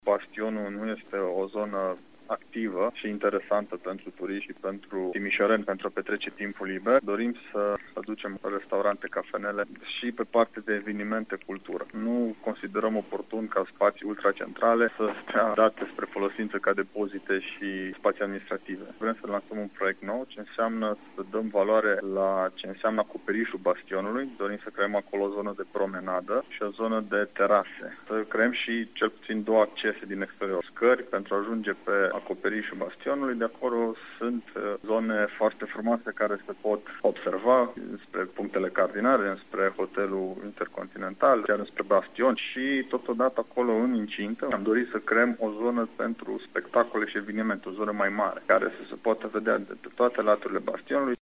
Acoperișul Bastionului ar urma să se transforme într-un loc de promenadă, astfel încât zona să devină atractivă pentru turiști, spune vicepreședintele Alexandru Proteasa.